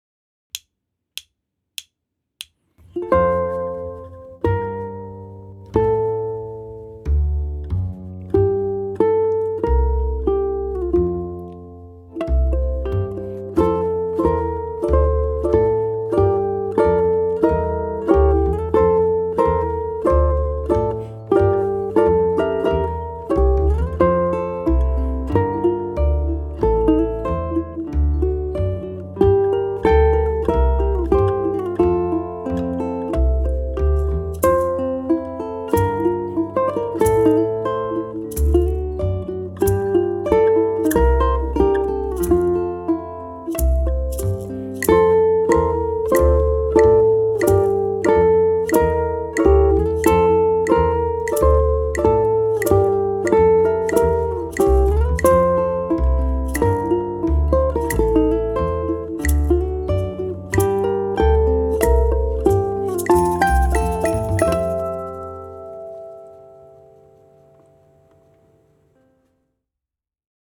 Gentle Mr. Bee (Včelka Mája), also known as The Honeybee, is a folk song from Bohemia.
I harmonized this song with old-time jazz changes of mostly two chords per measure, so your left-hand will keep busy.
In the audio track, I used a combination of the flat-four strum and P-i-m-a 2X arpeggio.
ʻukulele
Gentle_mr_bee_uke_mix.mp3